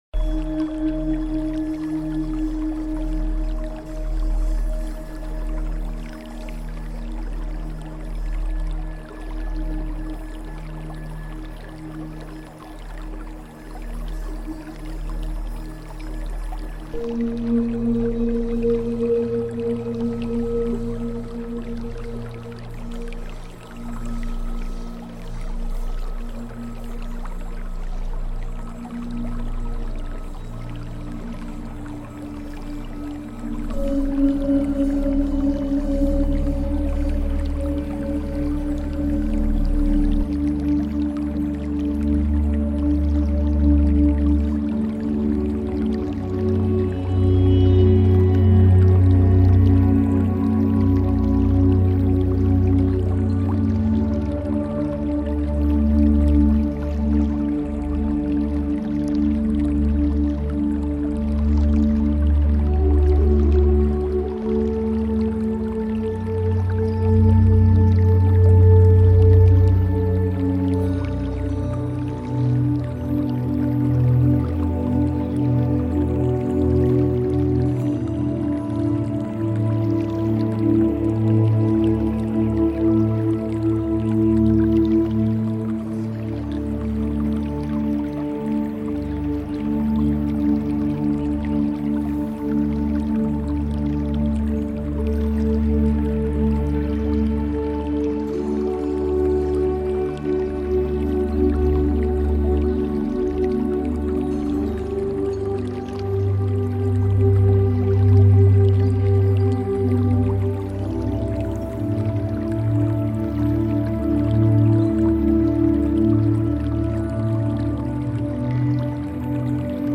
RUHE-PROGRAMMIERUNG: Tibet-Schwingung mit Gongs in tiefer Stille